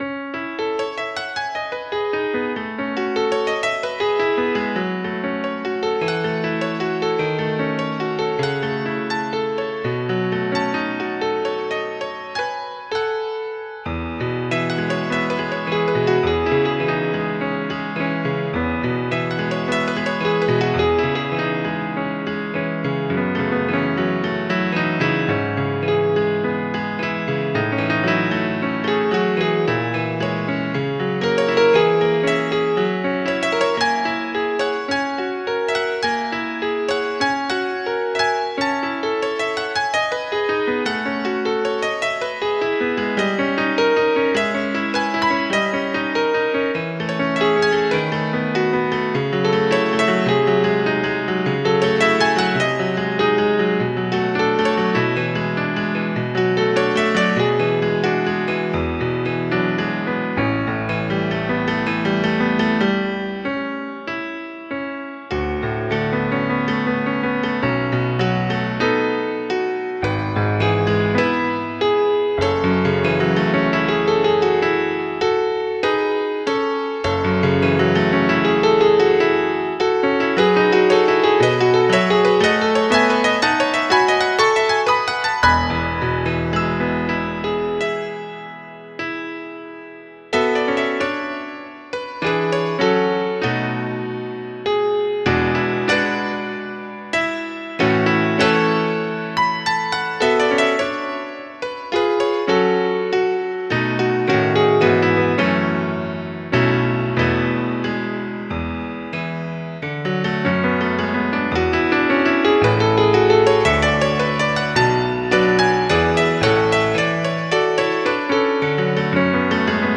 Claude Debussy, Arabesco número 1 (Andantino con moto) en Mi mayor, L. 66
acuático
arpegio
pianoforte
impresionismo